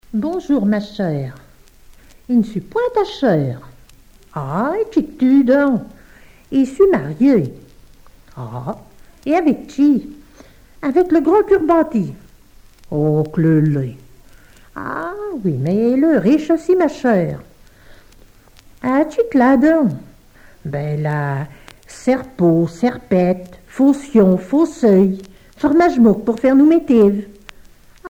Genre formulette
Catégorie Récit